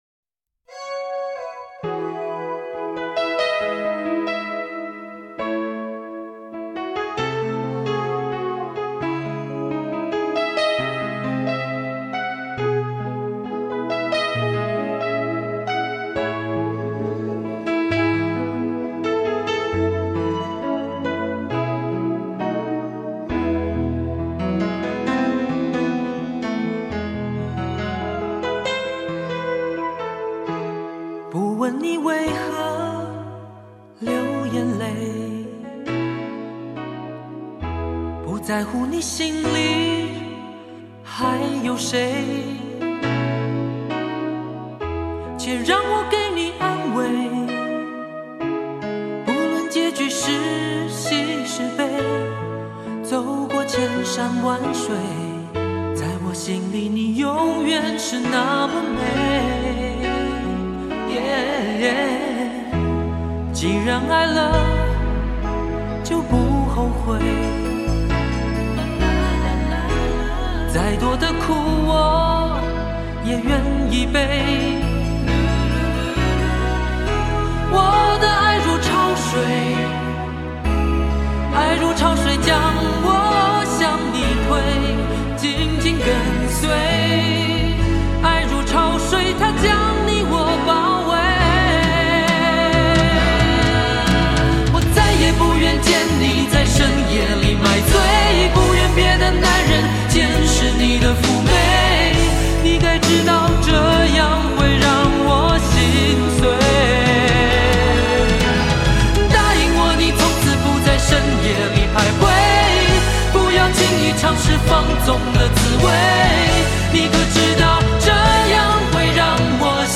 情歌精选